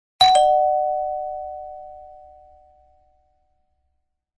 D430W kablet ringeklokke
To toner og volumforsterker.
• Permanent signal, alternativt ett signal med to toner og volumforsterker.
• Ca. 87 dB(A) – 750 mA